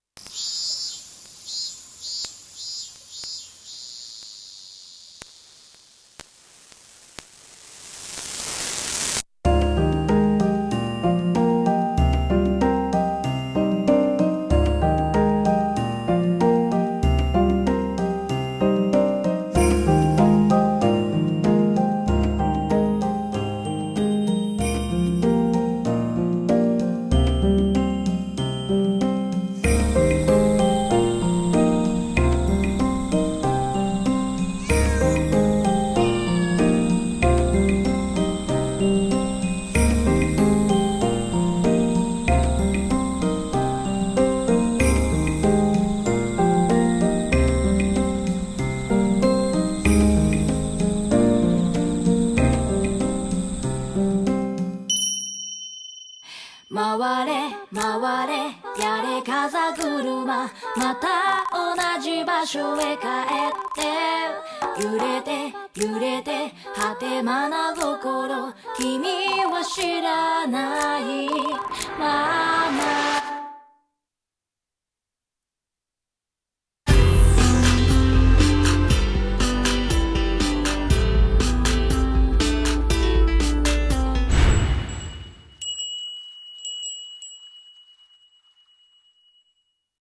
CM風声劇「風鈴燈籠」